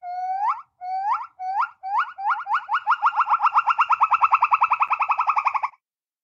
• Качество: 129, Stereo